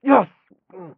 m_pain_6.ogg